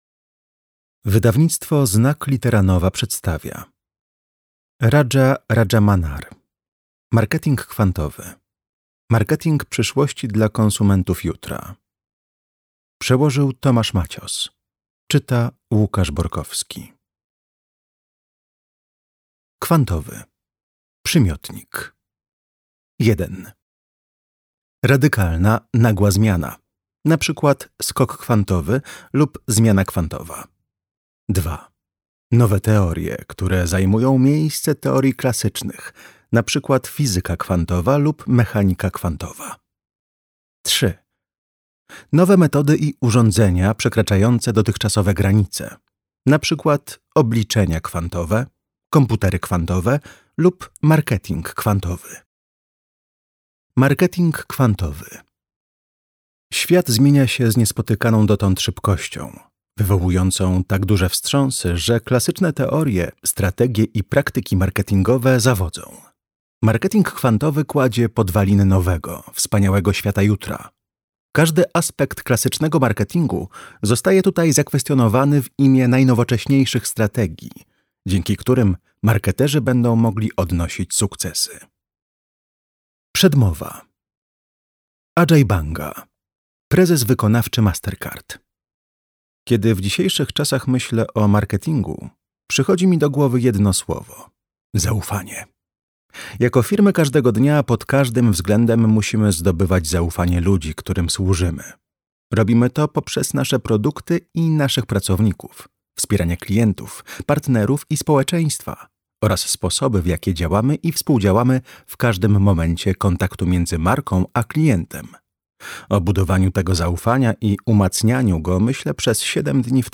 Marketing przyszłości dla konsumentów jutra - Rajamannar Raja - audiobook - Legimi online